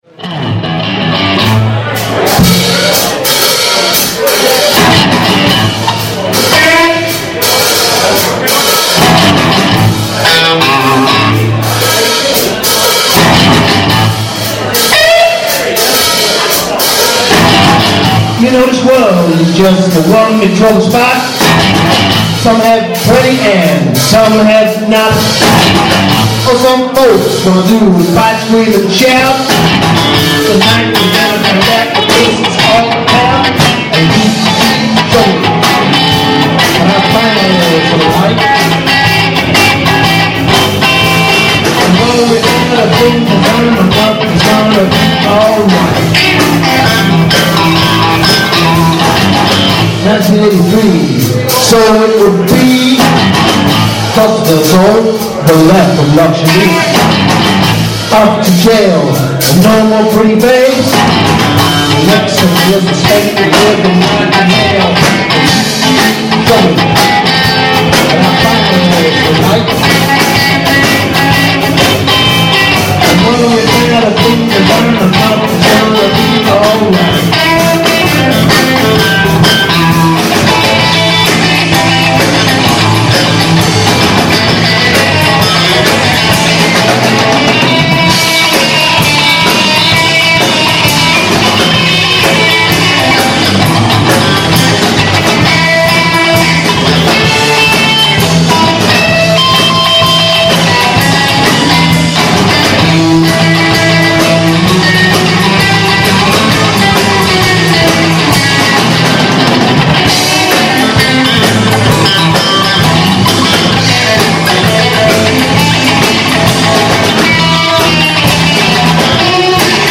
Rock Band Mojo perform at the Mytchett Beer Festival